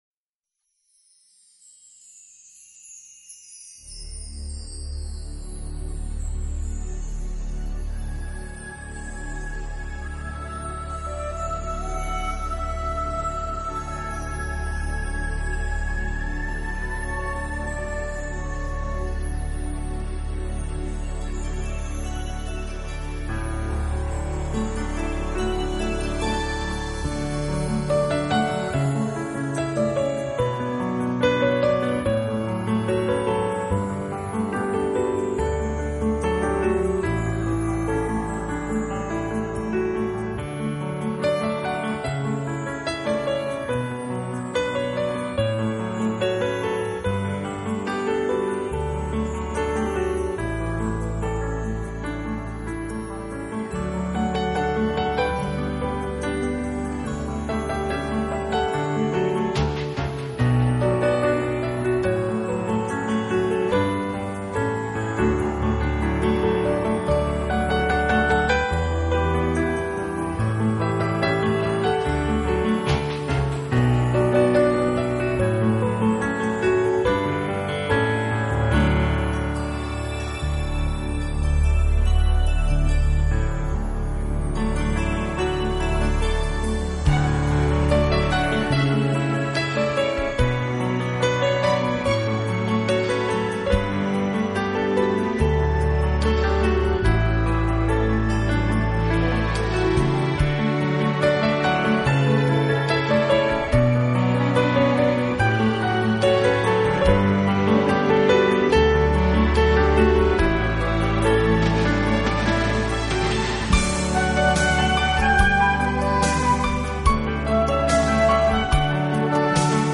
拉丁钢琴